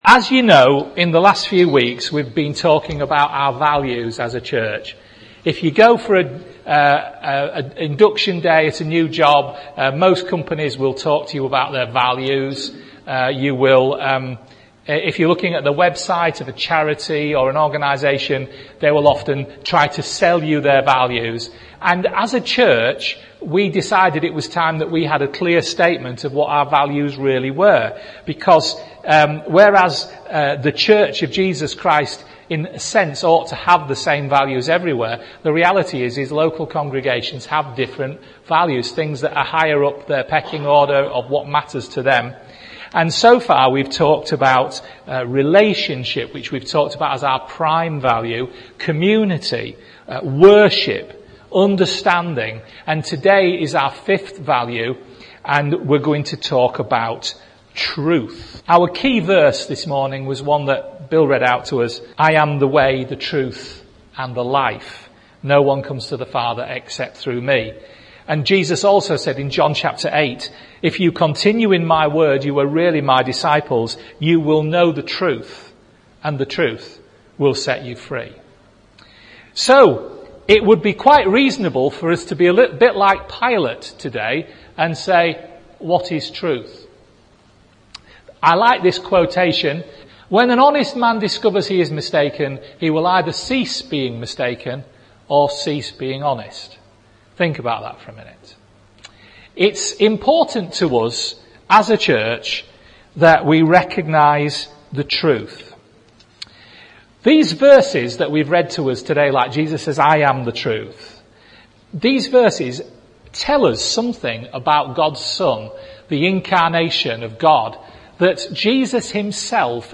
A preaching series that examines our core values as a church, ask ourselves why we hold them so precious, talk about their biblical basis and also what application we should see in our lives and in this church as we implement them.